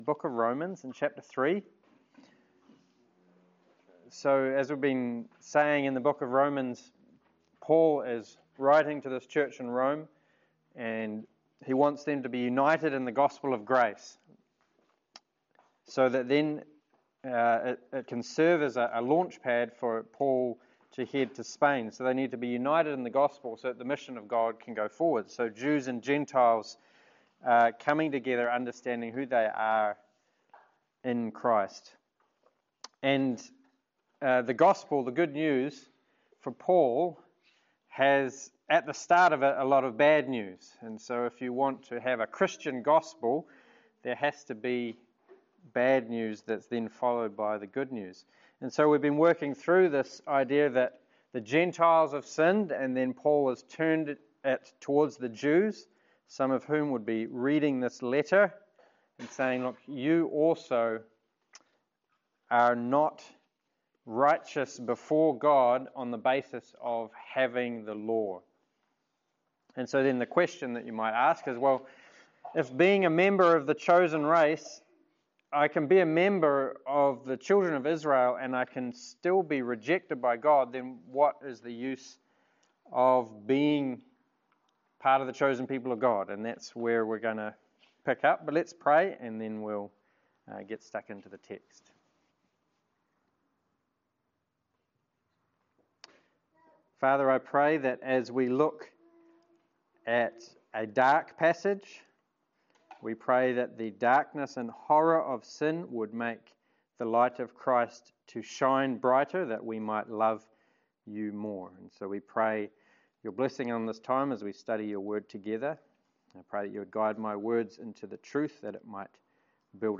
Romans 3:1-20 Service Type: Sermon As humans we are so prone to try to justify ourselves